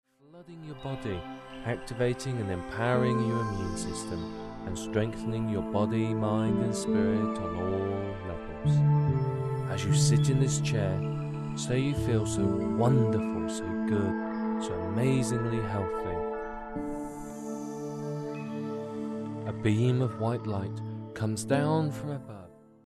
Aiding Arthritis Vocal Hypnosis MP3
The program consists of two professionally recorded hypnosis programs.